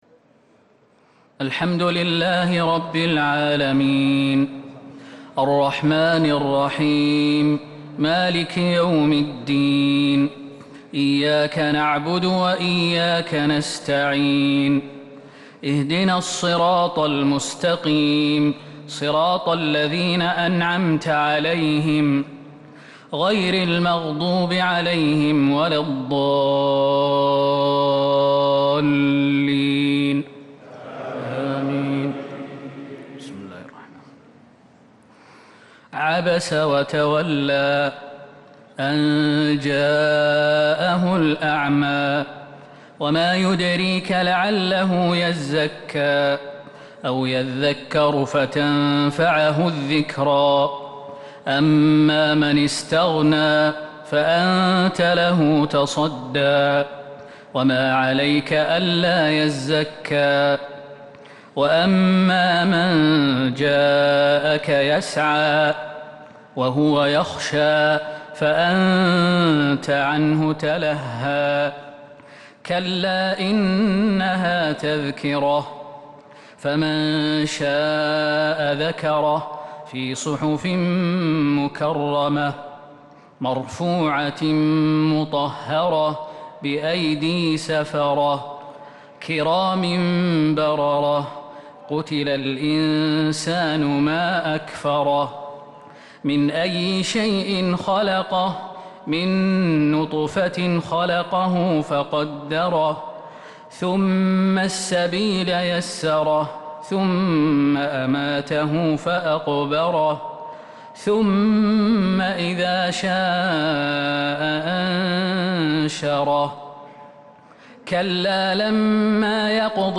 صلاة التراويح ليلة 29 رمضان 1443 للقارئ خالد المهنا - الثلاث التسليمات الأولى صلاة التراويح